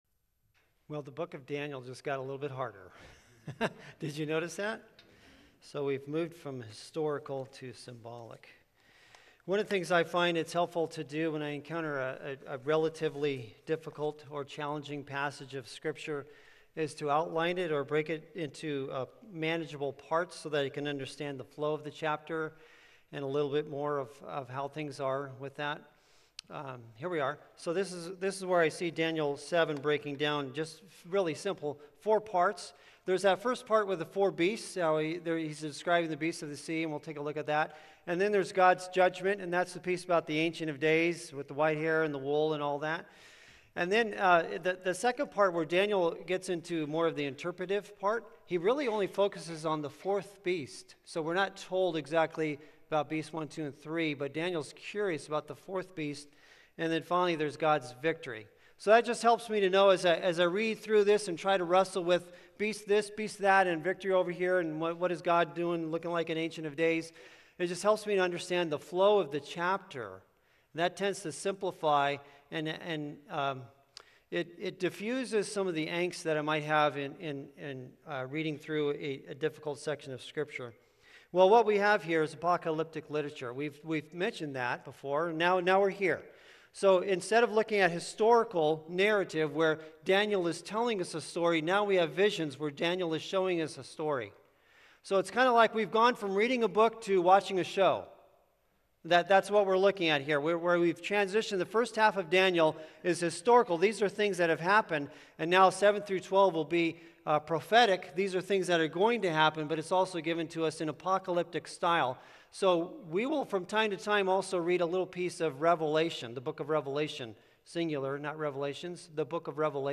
Sermons | Hope Community Church